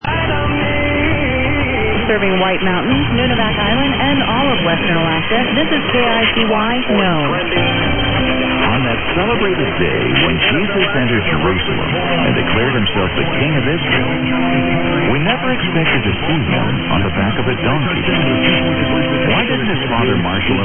I've posted a clip of KICY-850 blowing off KHHO Tacoma:
Quite good o/KKOH 1428 w/temp for Nome, then native man singing followed by Fats Domino "My Blue Heaven" 850 KICY, 1400 legal ID by woman, then "Insight for Living" program, way atop local KHHO 972 KBS, almost back to its normal strength today, but only for a while.
Winradio Excalibur
K9AY antenna